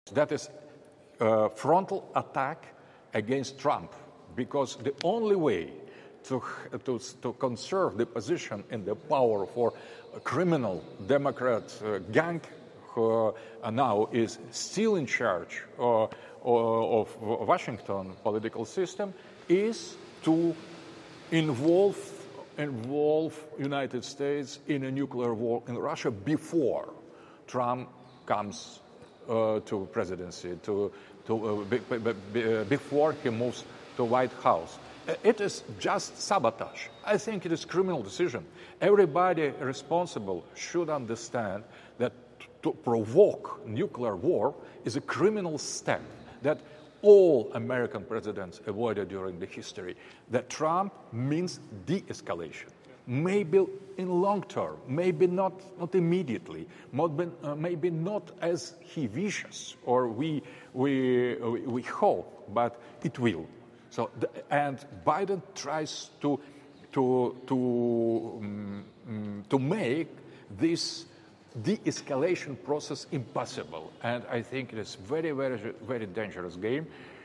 Администрация Джо Байдена стремится вовлечь США в ядерную войну с Россией до прихода Дональда Трампа в Белый дом. Об этом в разговоре с RT заявил философ Александр Дугин. Он отметил, что таким способом демократы пытаются удержаться у власти, а президентство Трампа в перспективе может привести к деэскалации.